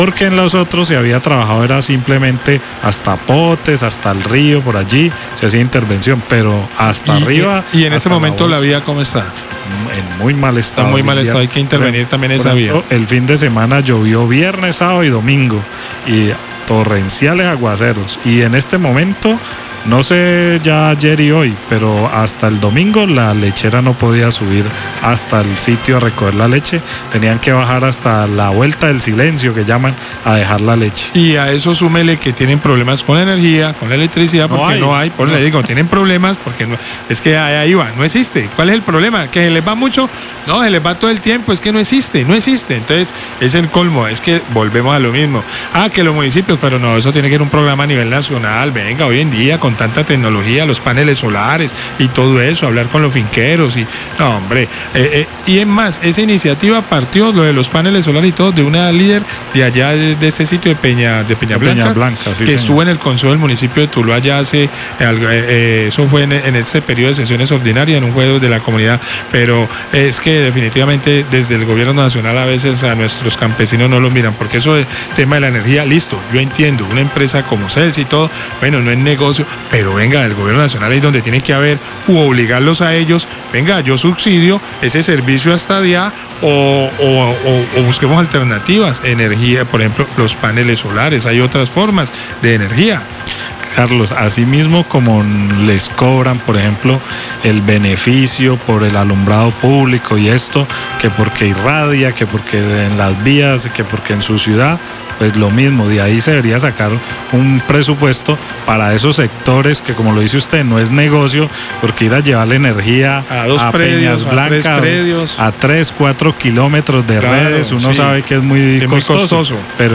Radio
Periodistas de La Cariñosa comentan las intervenciones en las vías de la zona rural tulueña y el abandono en el que el gobierno nacional tiene a los campesinos; en la vereda Peñas blancas todavía hay familias sin el servicio de energía y aunque los paneles serían una solución viable el gobierno nacional sigue sin llegar a estos lugares.